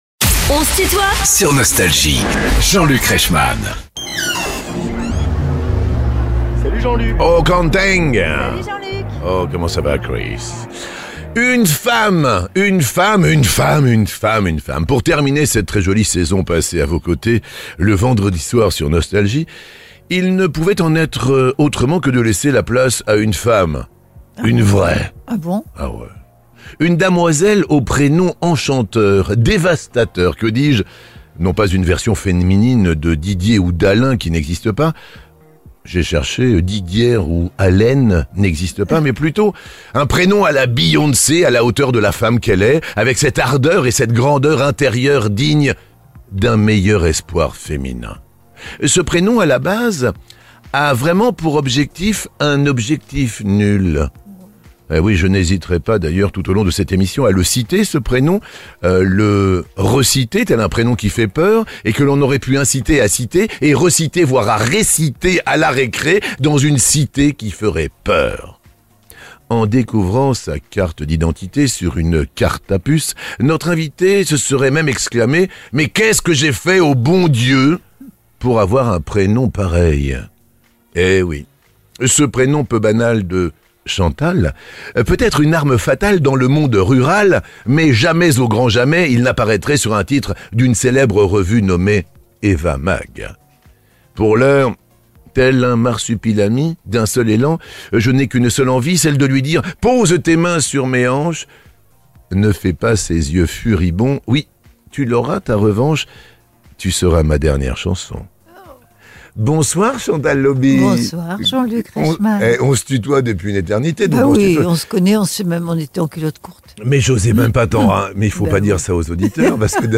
Chantal Lauby est l'invitée de "On se tutoie ?..." avec Jean-Luc Reichmann (Partie 1) ~ Les interviews Podcast
Les plus grands artistes sont en interview sur Nostalgie.